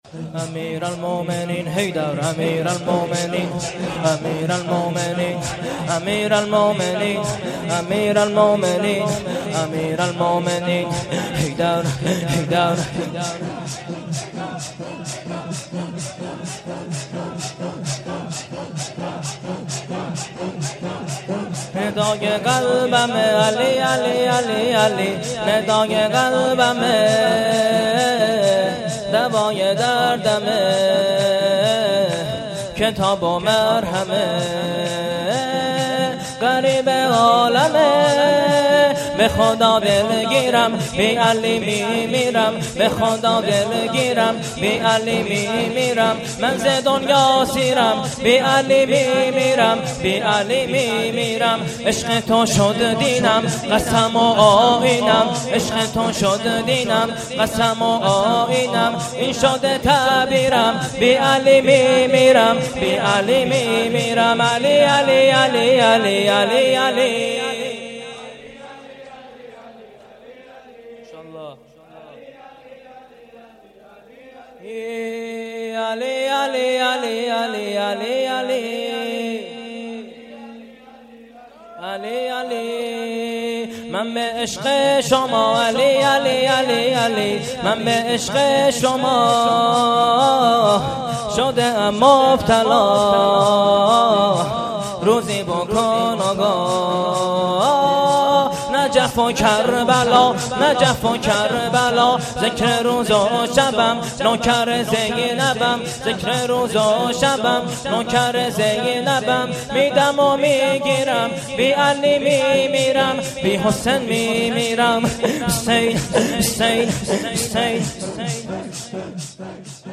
شور|ندای قلبمه